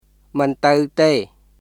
[ムン・タウ・テー　mɯn tə̀w tèː]